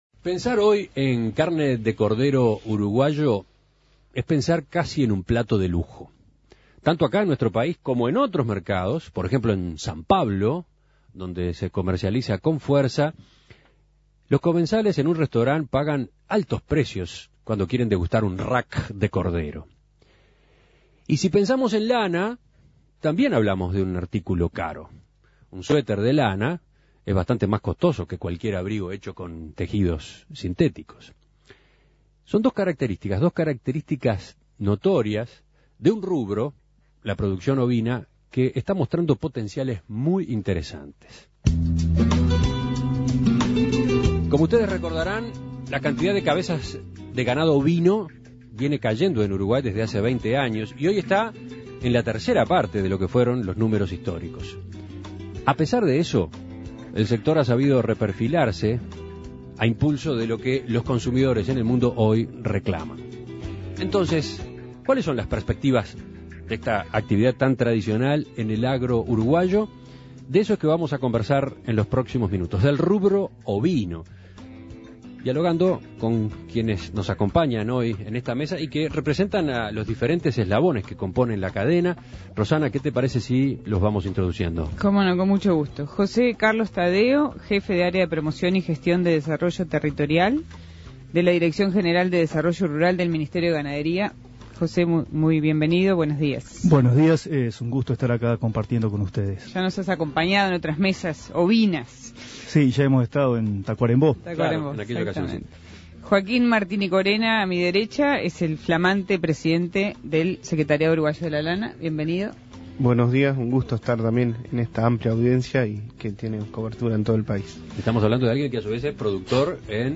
Con este panorama como contexto, ¿qué está pasando con el rubro ovino en Uruguay? Para profundizar en este tema, En Perspectiva reunió a cuatro representantes de los productores de la industria frigorífica, textil y el sector público.